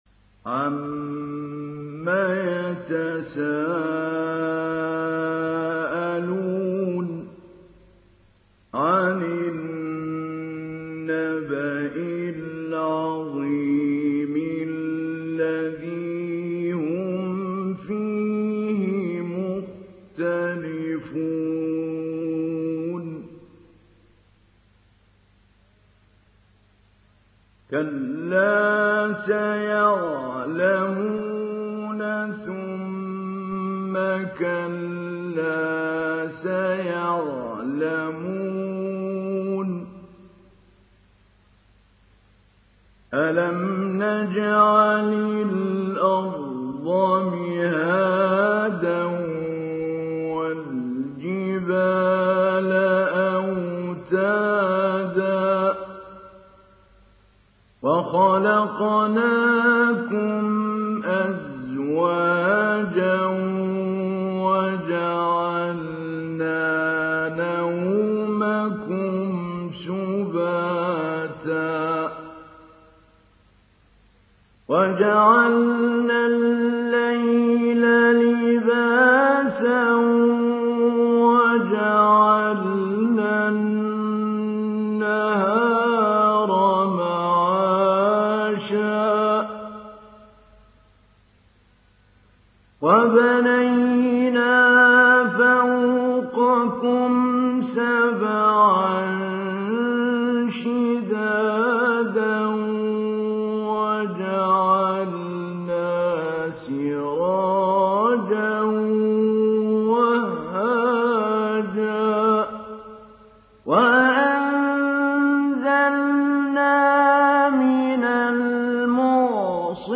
دانلود سوره النبأ محمود علي البنا مجود